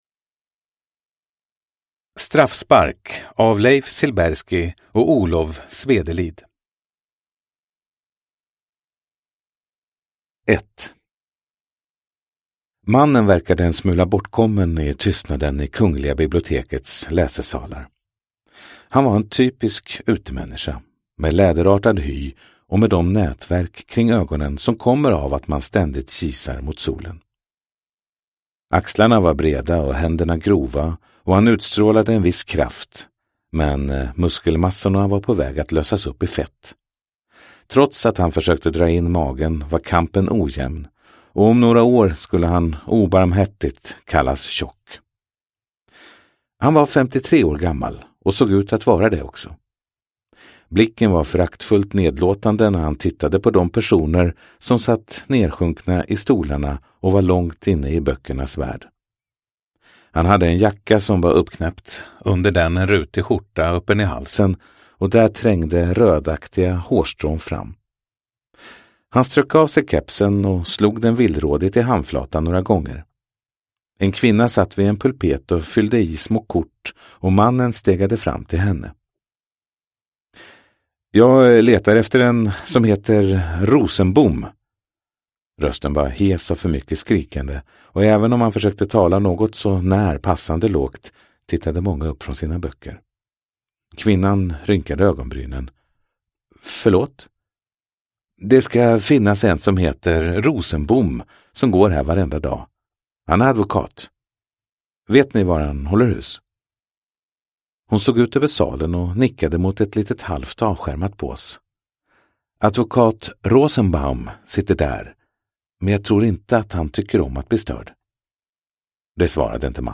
Straffspark – Ljudbok